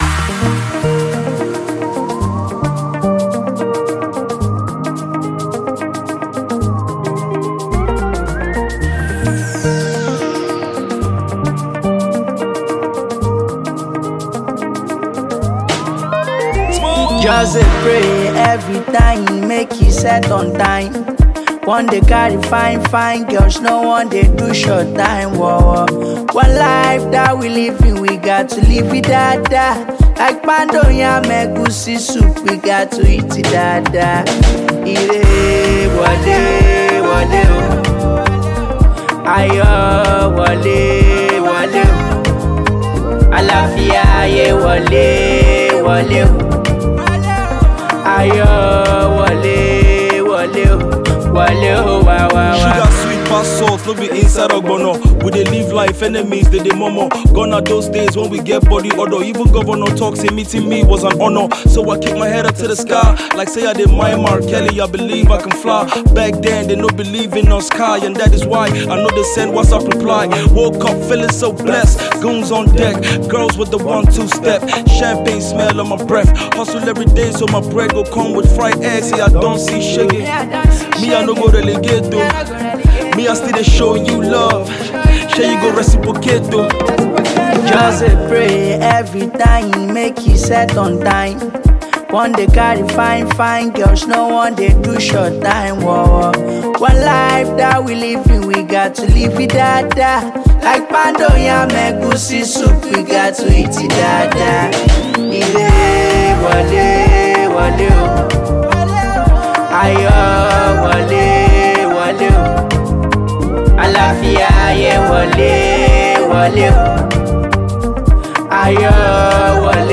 Nigerian talented Afrobeats singer